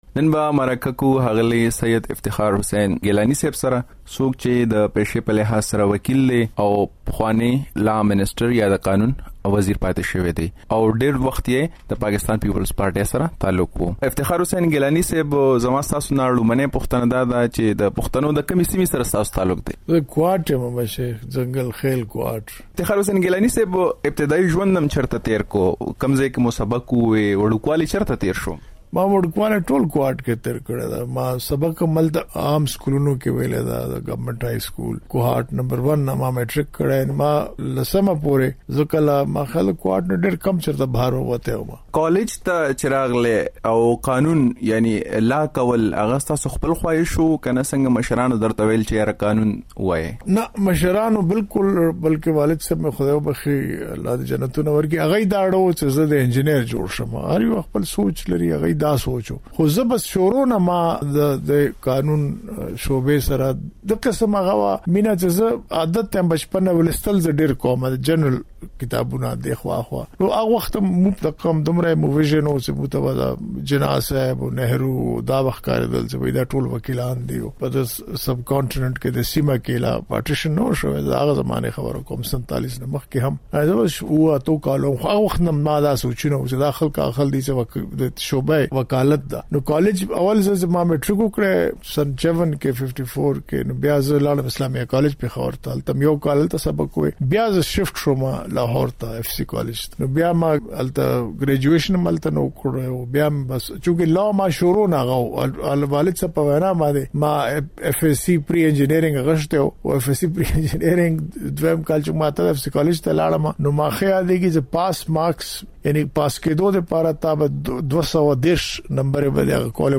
دنن ځانګړې مرکه له افتخار ګیلاني سره شوېده